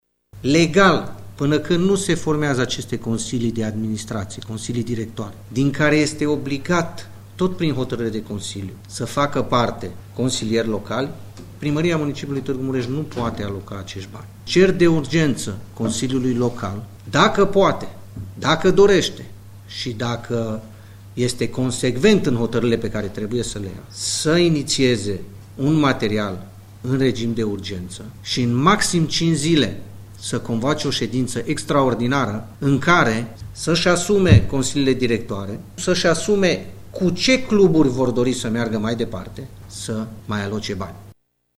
în cadrul unei conferințe de presă